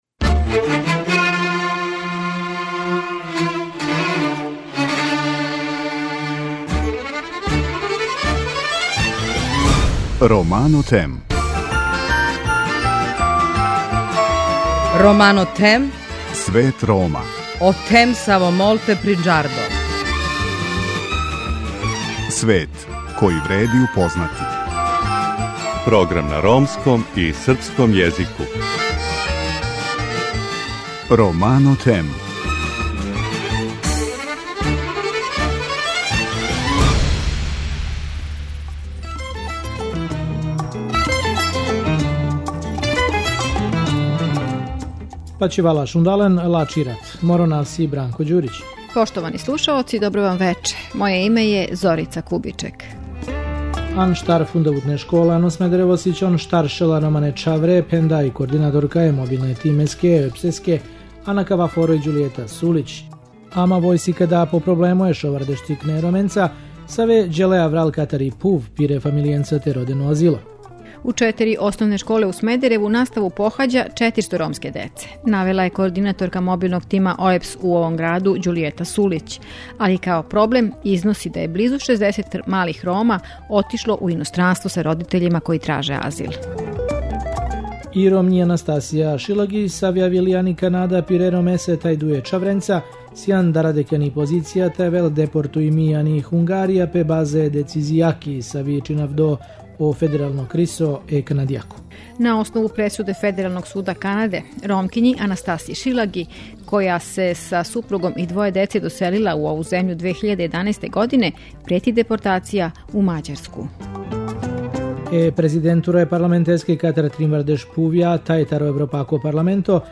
У вечерашњој емисији слушамо потресна сведочења преживелих Рома логораша из Аушвица, која су забележили новинари "Радио Романо" из Шведске.